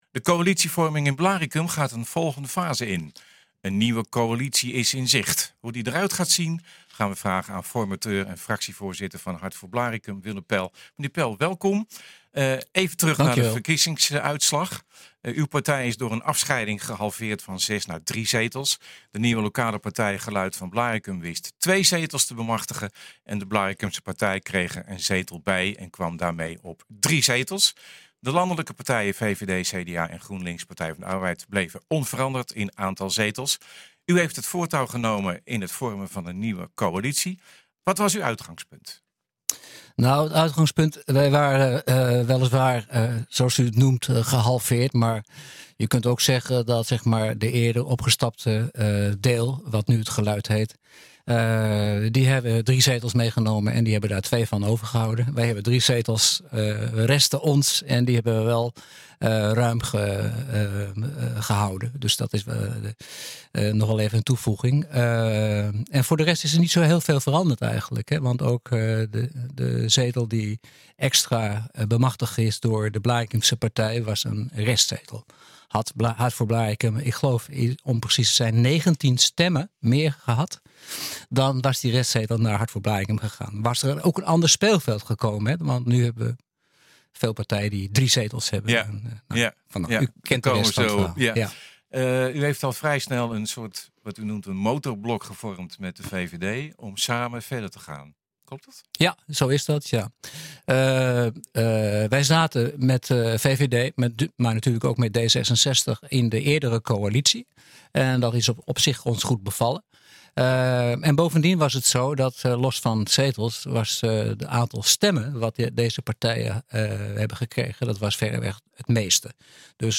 Formateur en fractievoorzitter van Hart voor Blaricum Willem Pel
Hoe die eruit gaat zien, vragen we aan formateur en fractievoorzitter van Hart voor Blaricum Willem Pel.